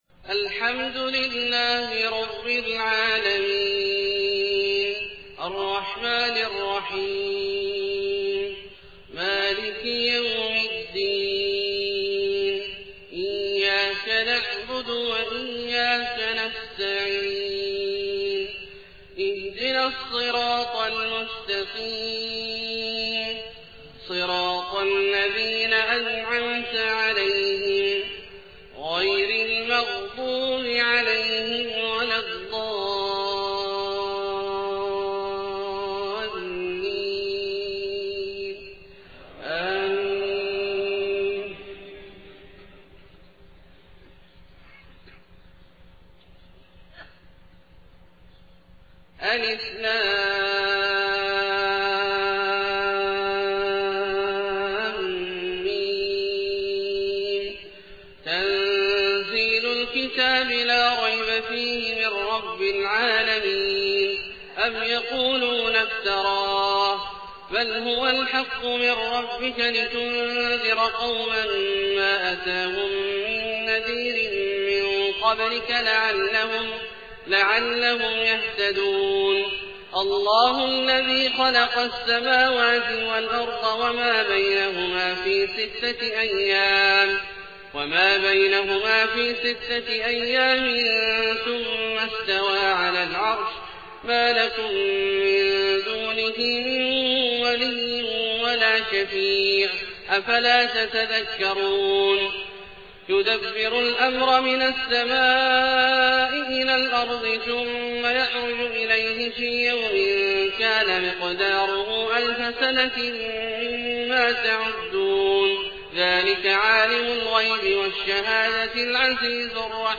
صلاة الفجر 1 محرم 1431هـ سورتي السجدة و الانسان > 1431 🕋 > الفروض - تلاوات الحرمين